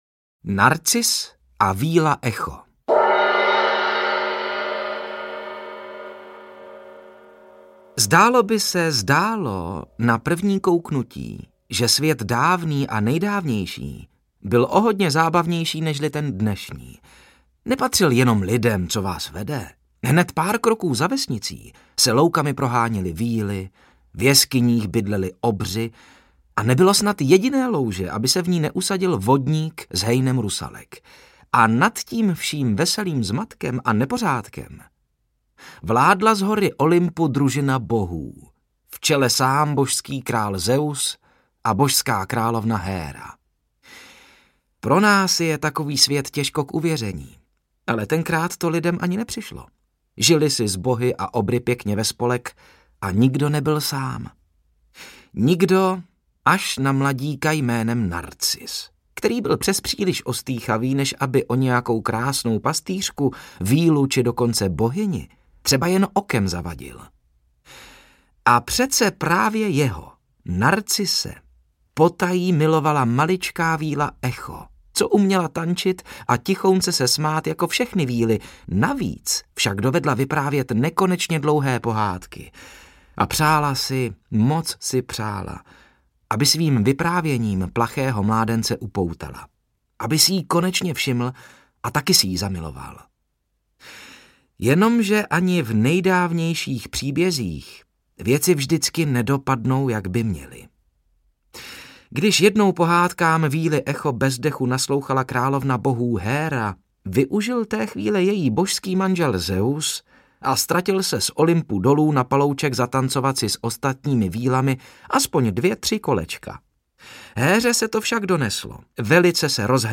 Audio knihaDiogenes v sudu, Damoklův meč a další známé příběhy z doby dávné a nejdávnější
Ukázka z knihy